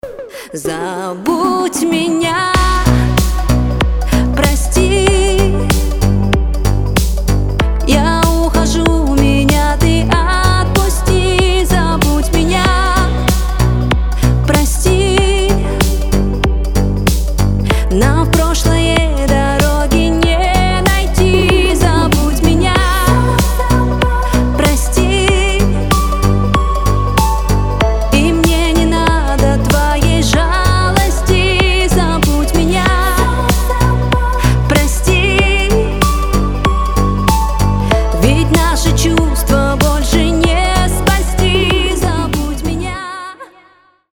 танцевальные , женский голос